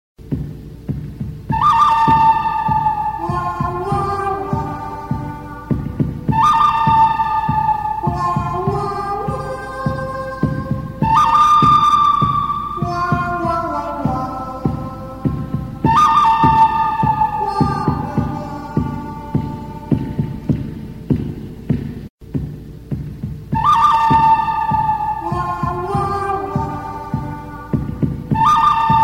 وسترن به سبک زنگ گوشی